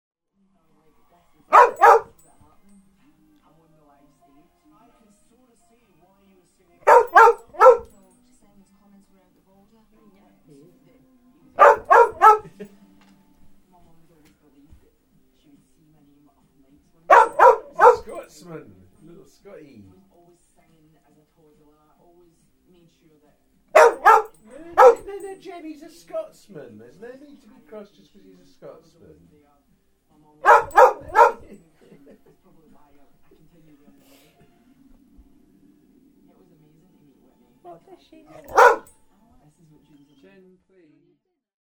barking insistently and agitatedly through the whole programme.